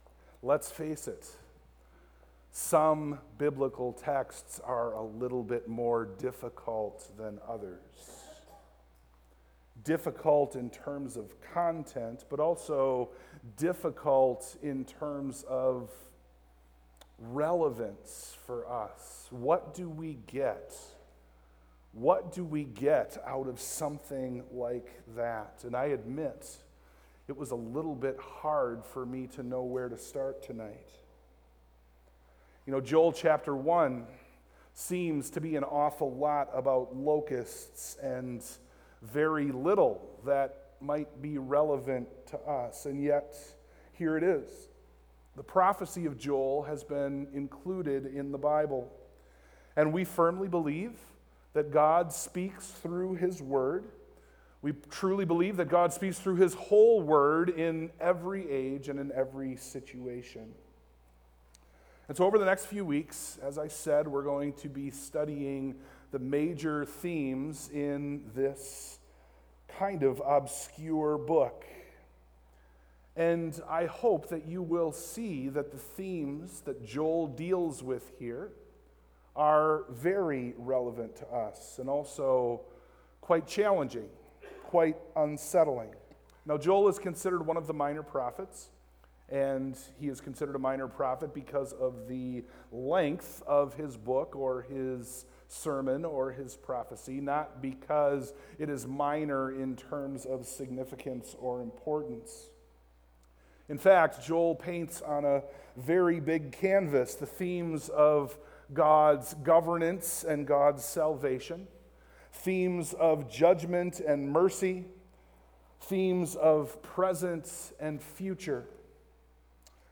Service Type: PM
Sermon+Audio+-+The+Day+of+the+Lord.mp3